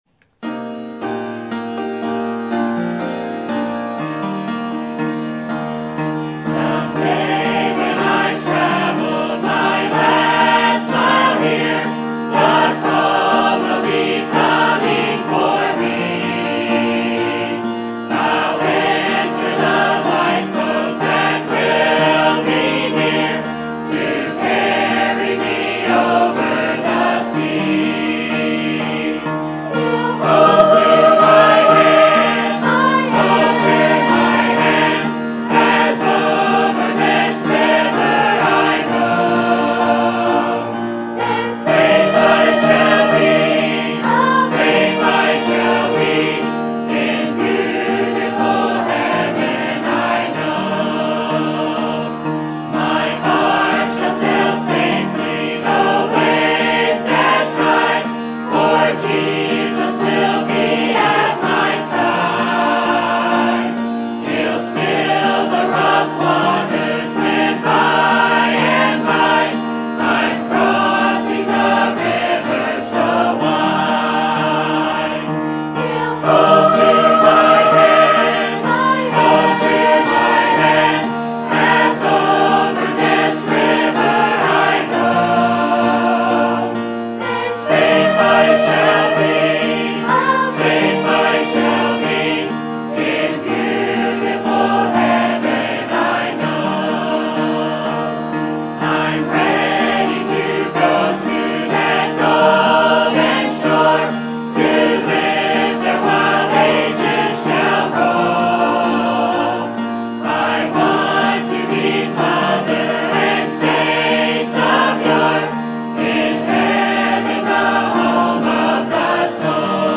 Sermon Topic: Spring Revival Sermon Type: Special Sermon Audio: Sermon download: Download (30.9 MB) Sermon Tags: Genesis Revival Faith Trust